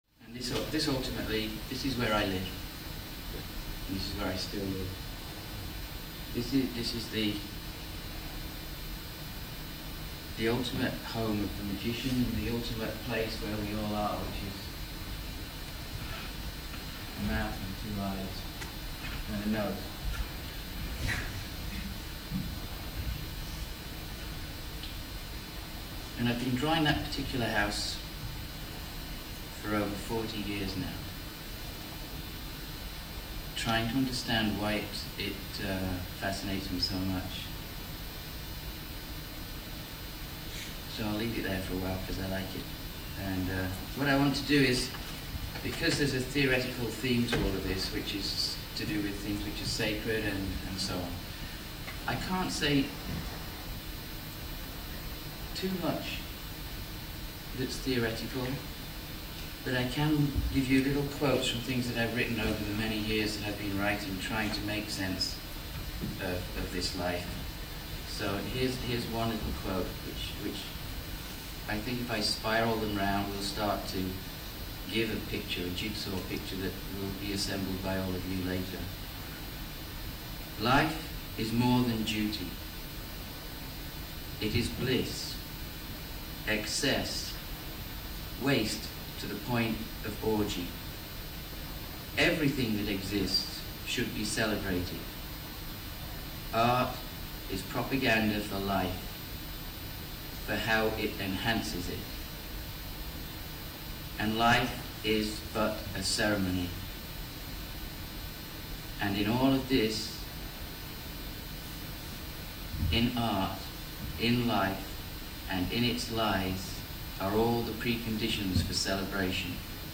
University of Central Lancashire & Guild Hall Preston
Keynote Lecture
lecture_excerpt_256.rm